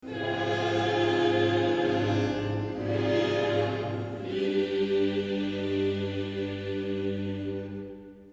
Eine überraschende Wendung am Ende, eine Schlusswendung von f-moll nach G-Dur.